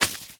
Minecraft Version Minecraft Version 25w18a Latest Release | Latest Snapshot 25w18a / assets / minecraft / sounds / entity / leashknot / break2.ogg Compare With Compare With Latest Release | Latest Snapshot